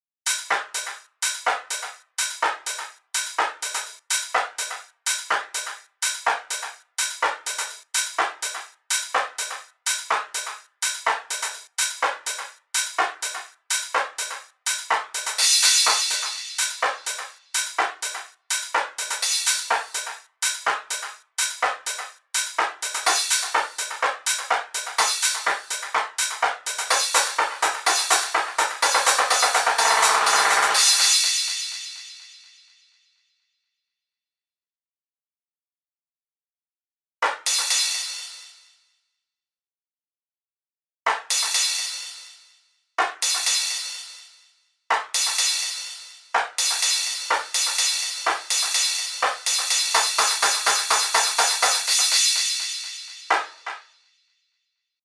So now there is also a bass drum, and a crash.
The open hi-hats are playing the same part they did in the first build up, hitting on the ‘&’ of every beat.
Here is a bounce of the drums depicted here, with no FX or automation:
Drums-Build-2.mp3